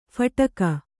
♪ phaṭaka